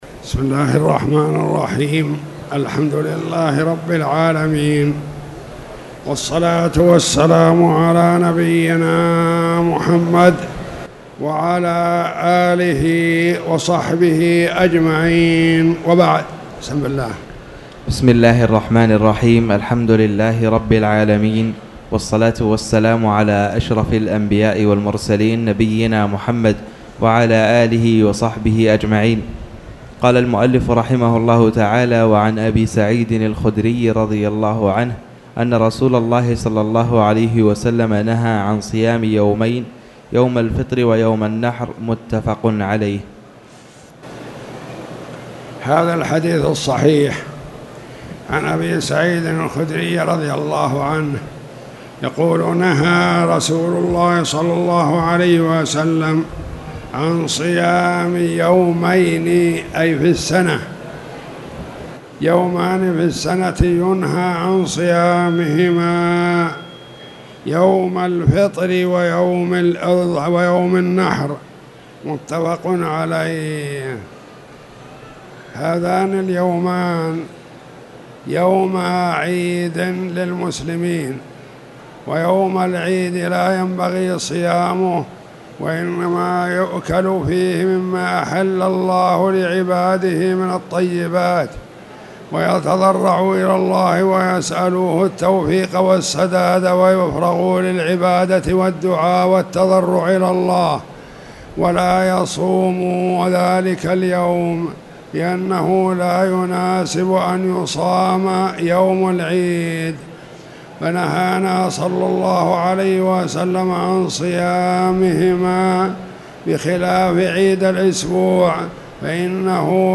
تاريخ النشر ١٩ شوال ١٤٣٧ هـ المكان: المسجد الحرام الشيخ